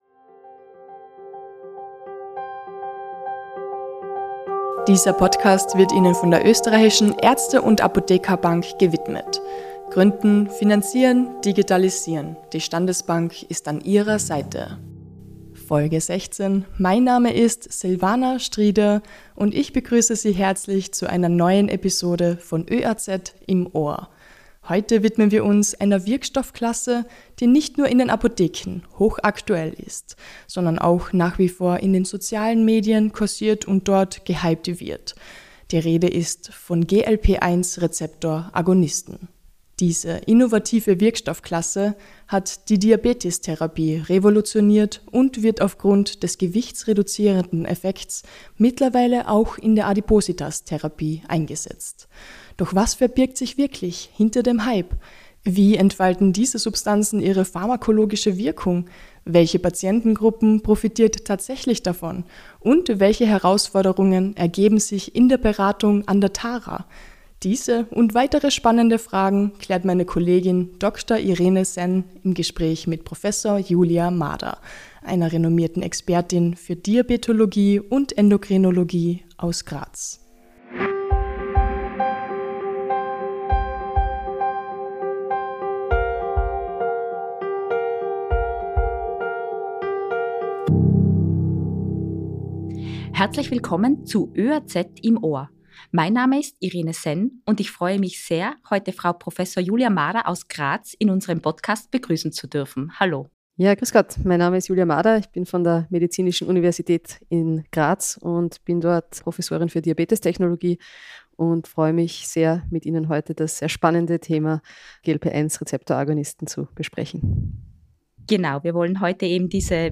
Disclaimer Dieser Podcast richtet sich an Fachkreise und dient der Vermittlung von allgemeinem Wissen über pharmazeutische und medizinische Themen.